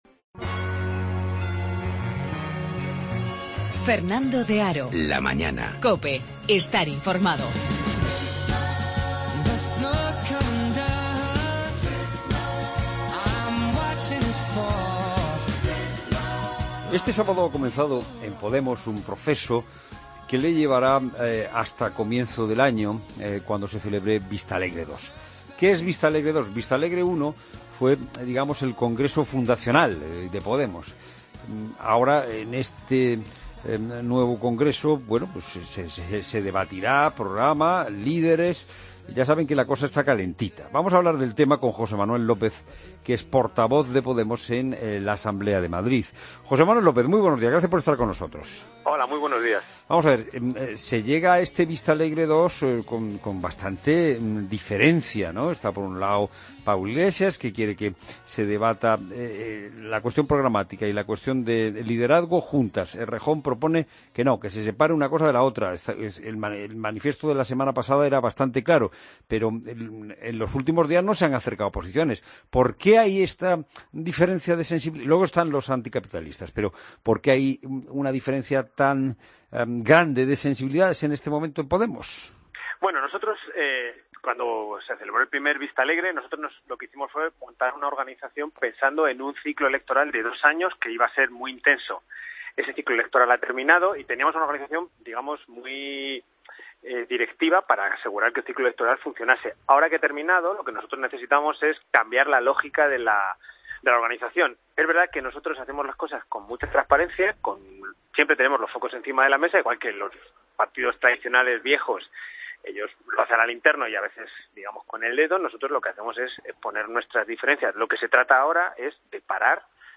José Manuel López, portavoz de Podemos en la Asamblea de Madrid en 'La Mañana de Fin de Semana' COPE